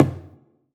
PERC - ALTITUDE.wav